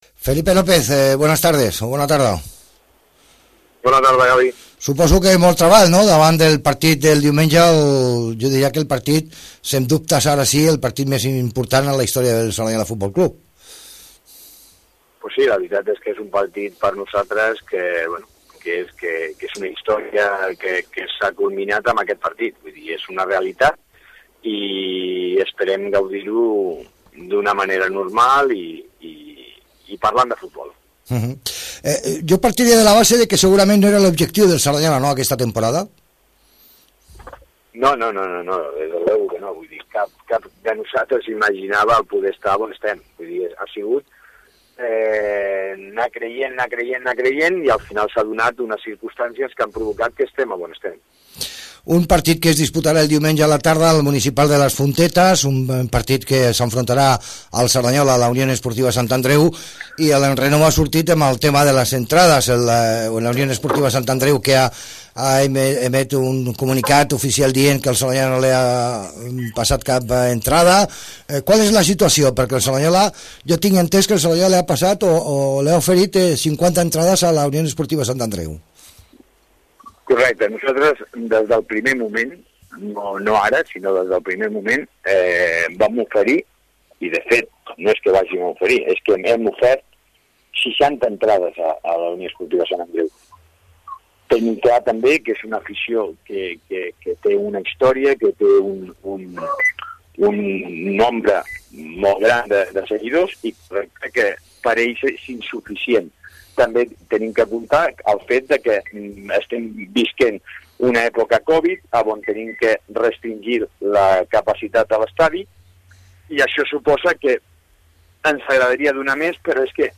en declaracions a Cerdanyola Ràdio